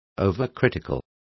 Complete with pronunciation of the translation of overcritical.